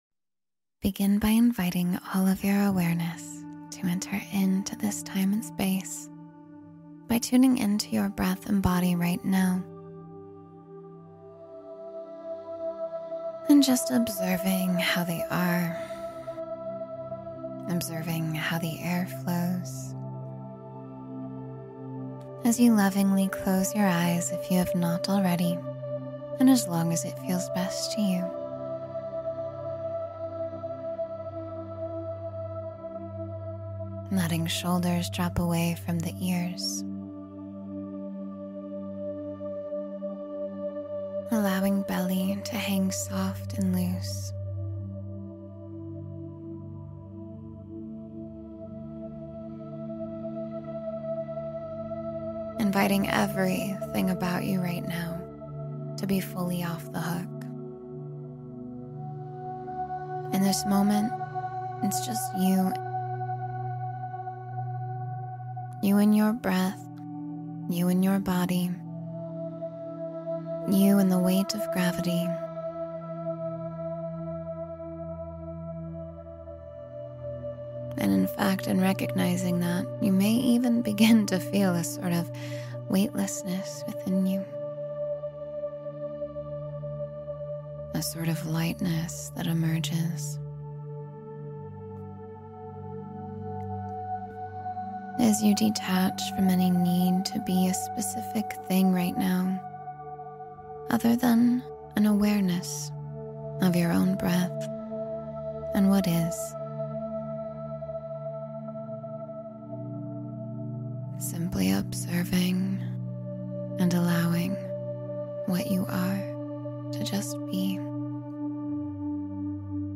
Forgive Yourself and Begin Again — Meditation for Emotional Healing